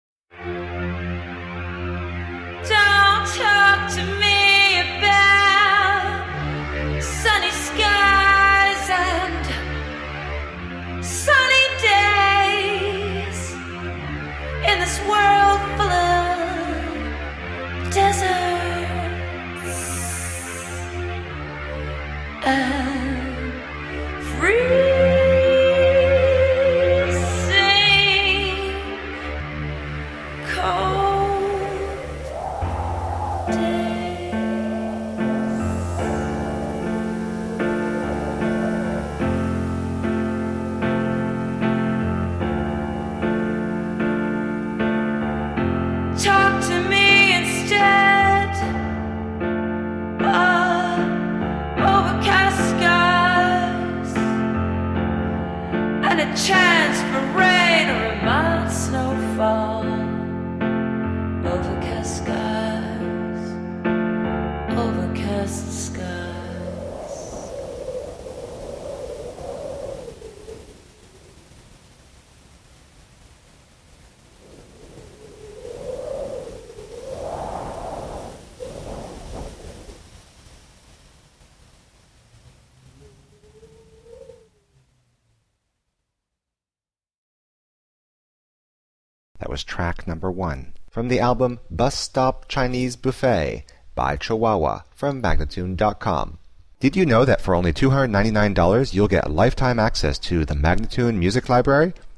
alternative/rock band
The infectious grooves and luscious melodies
five octave range voice
Some call it post modern post punk electro-acoustic hybrid.
Tagged as: Alt Rock, Pop